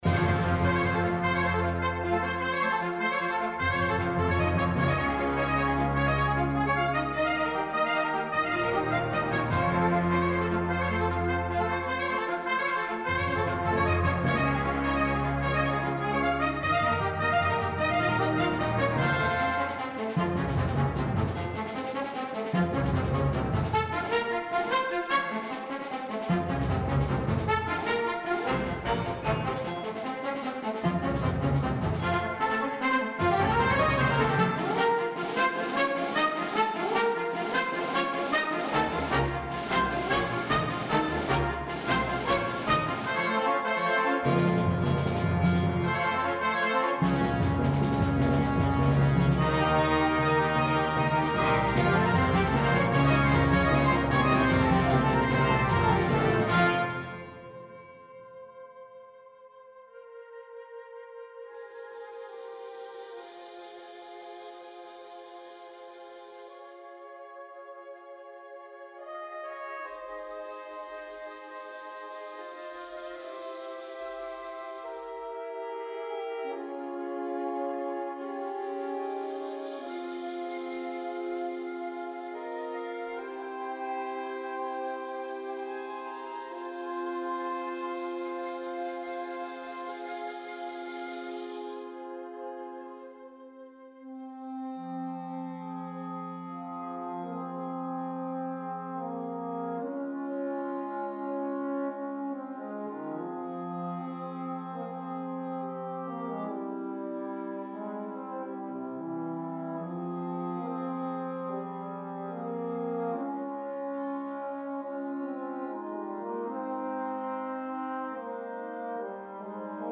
Die Komposition besteht aus drei zusammenhängenden Sätzen.
Besetzung: Concert Band